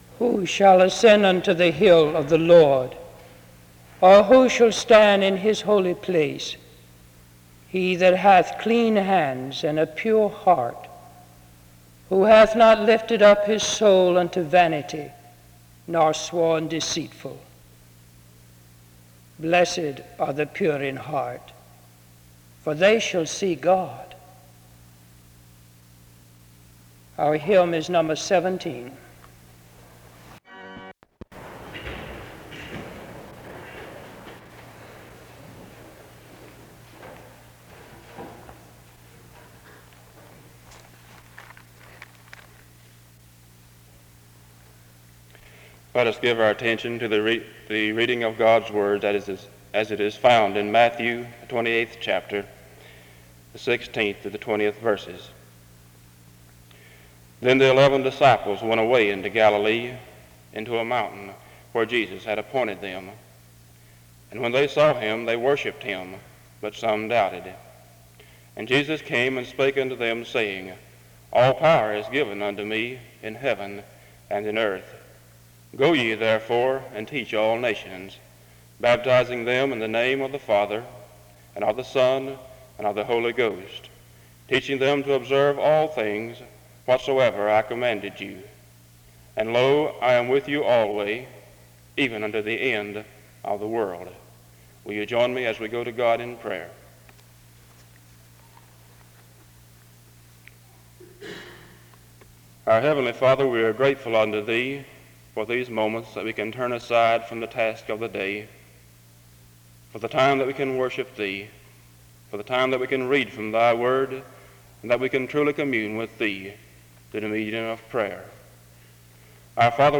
The service opens with a scripture reading from 0:00-0:30.
A prayer is offered from 1:38-2:49. An introduction to the speaker is given from 2:57-4:25. The choir sings from 4:30-8:23.